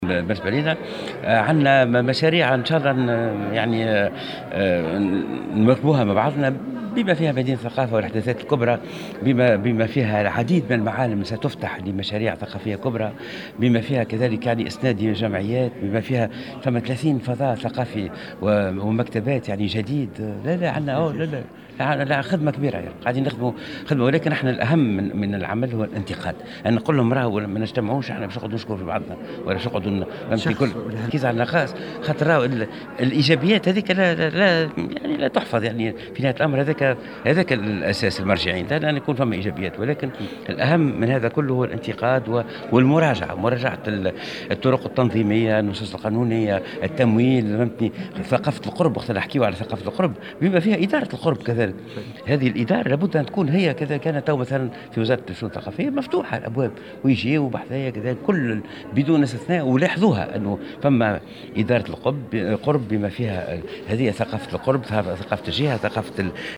وشدد وزير الثقافة في تصريح لمراسل "الجوهرة اف أم" على اهمية مراجعة الطرق التنظيمية والنصوص القانونية والتمويل .